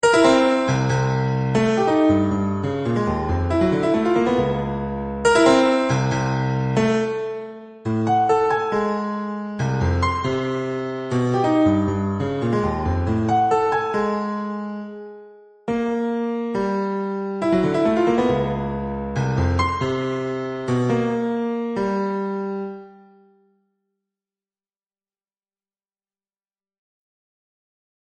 Les mesures répétées créent chaque fois une relative cohérence du morceau, mais c'est tout de même pour les petites tailles n que cela fonctionne le mieux.